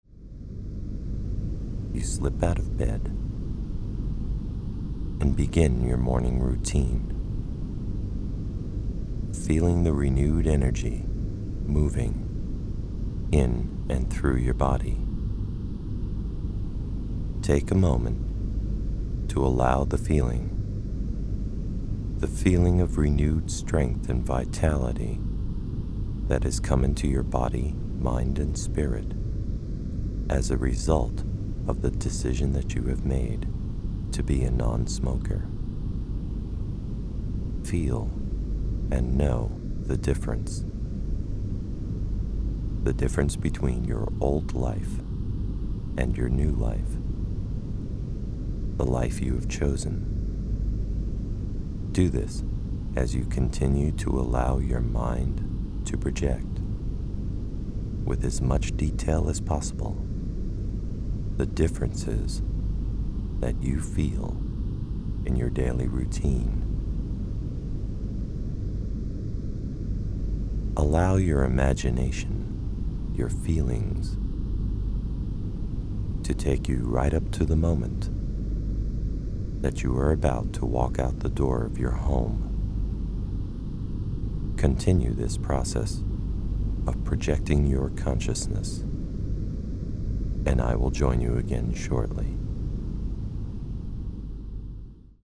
Zvuková stopa 1 (Freedom From Smoking) používá verbální vedení a Hemi-Sync® k posílení propojení Vaší mysli a těla a zaměřuje se na Vaše vnitřní Já - mentální, emocionální a tělesné a na Váš cíl stát se nekuřákem.
Verbální vedení: Anglické verbální vedení